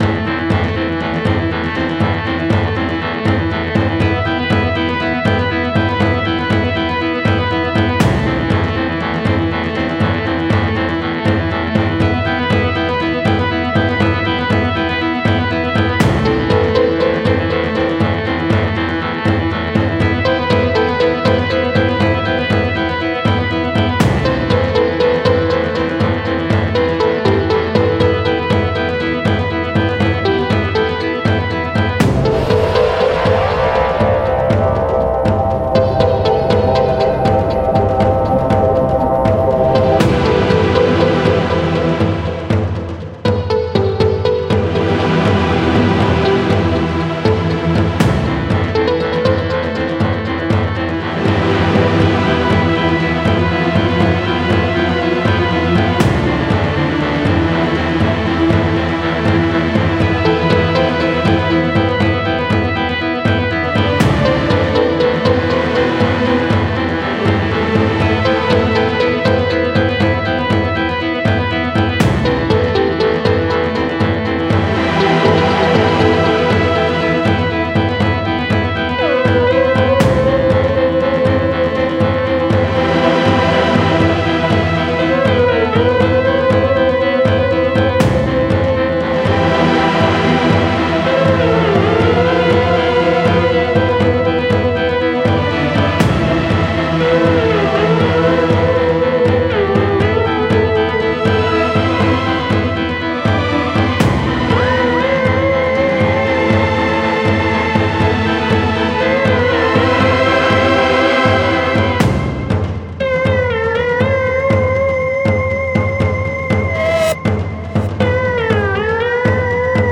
Pentru a exemplifica ceea ce s-a petrecut pe Zegma, pe Altfir, pe Ta-uka-1 și pe Ucub, am scris câteva secvențe muzicale, Tar’uk Embassy este una din ele , probabil veți sesiza că începe și se termină abrupt, de fapt intro-ul și finalul au frecvențe sub 40 Hz și peste 20,000 de Hz. Din păcate, urechea umană nu „aude” aceste sunete.